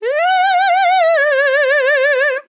TEN VOCAL FILL 16 Sample
Categories: Vocals
dry, english, female, fill
POLI-VOCAL-Fills-100bpm-A-16.wav